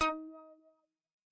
描述：通过Modular Sample从模拟合成器采样的单音。